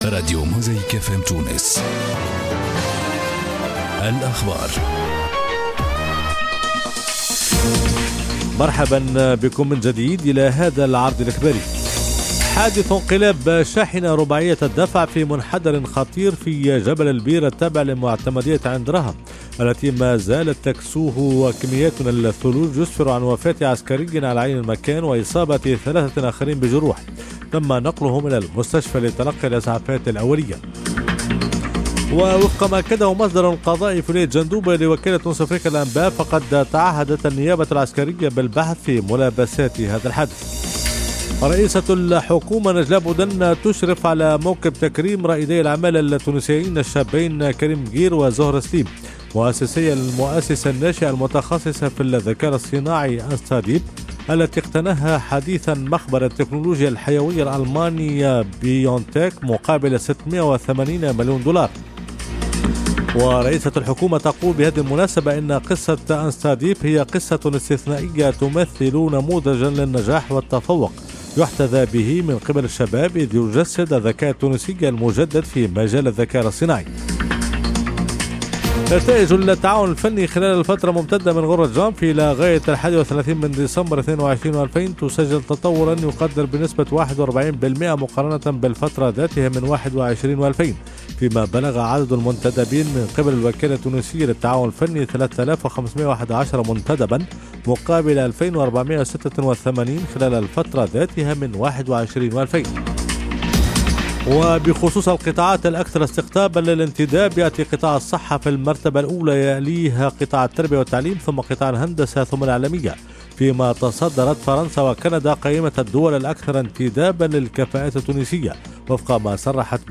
نشرات أخبار جانفي 2023